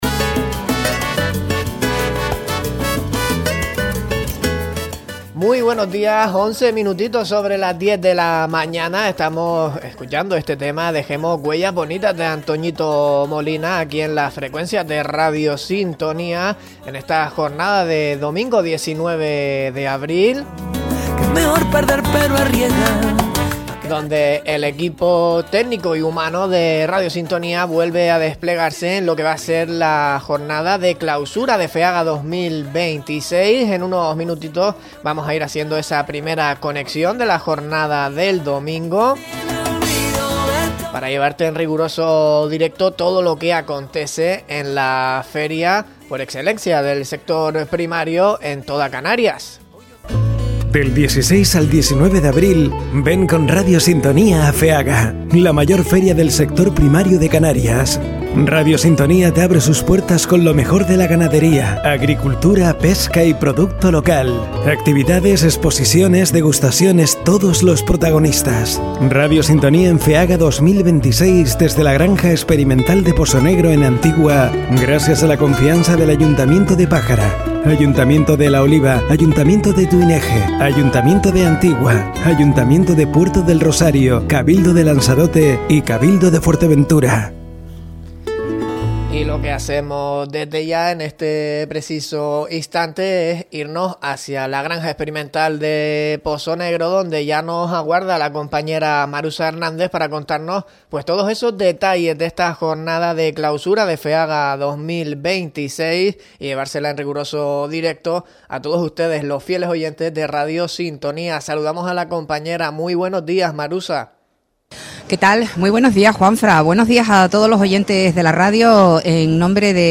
En la jornada de clausura de Feaga 2026 conocemos las impresiones sobre la feria por parte de Andrés Díaz Matoso, director general de ganadería del Gobierno de Canarias y Esteban Reyes Hernández, director general de pesca del Gobierno de Canarias.
Entrevistas